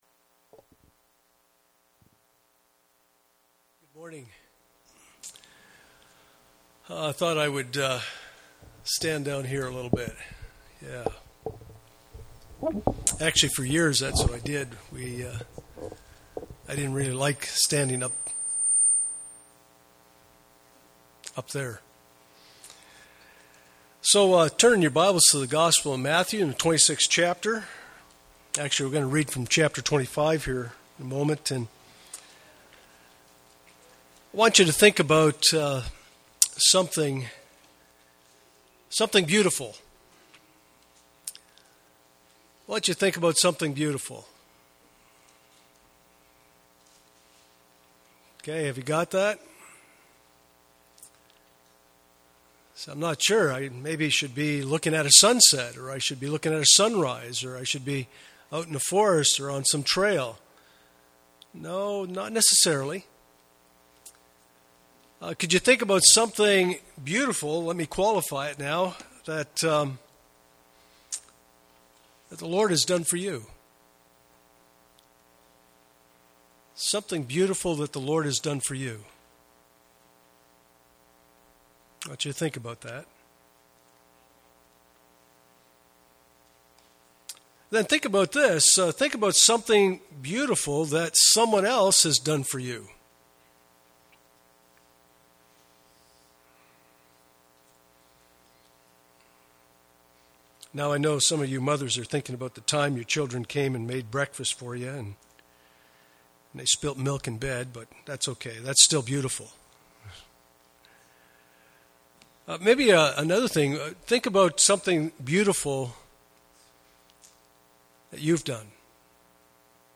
Passage: Matthew 26:1-13 Service Type: Sunday Morning